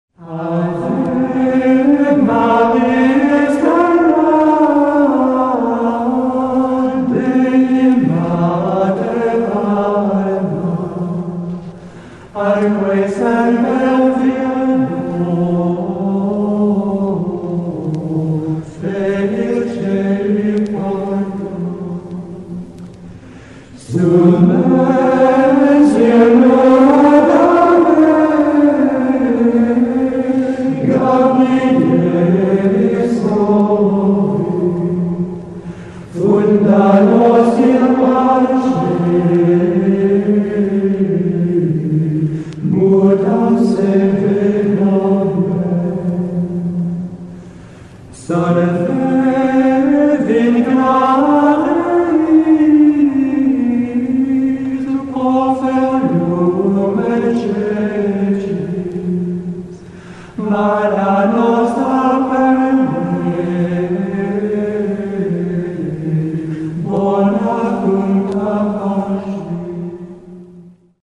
THROUGH COMPOSED MELODY
A through composed melody is a melody that continues to develop as you hear it.
Much of improvisation is through composed and so is ancient chant.
gregorianchant1.mp3